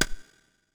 PixelPerfectionCE/assets/minecraft/sounds/item/shovel/flatten2.ogg at mc116